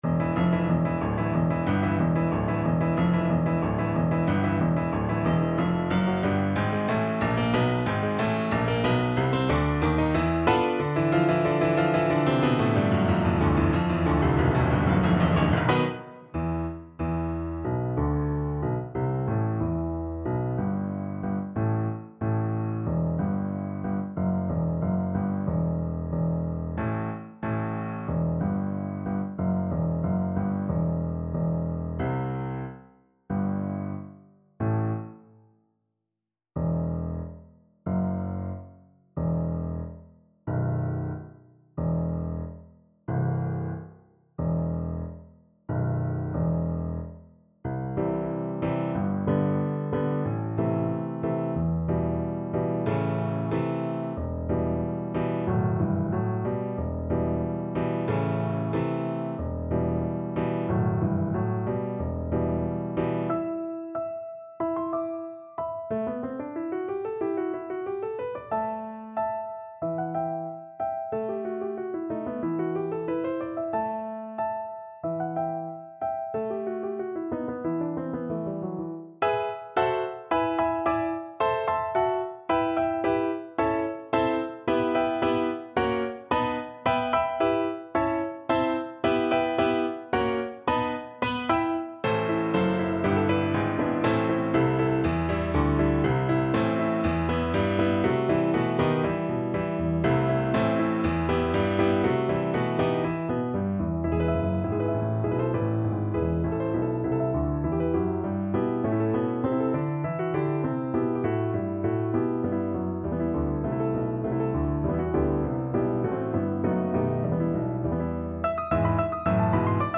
Play (or use space bar on your keyboard) Pause Music Playalong - Piano Accompaniment Playalong Band Accompaniment not yet available reset tempo print settings full screen
2/4 (View more 2/4 Music)
E minor (Sounding Pitch) (View more E minor Music for Viola )
Allegro =92 (View more music marked Allegro)
Classical (View more Classical Viola Music)